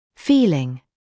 [‘fiːlɪŋ][‘фиːлин]чувство; ощущение